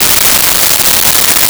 Crickets
Crickets.wav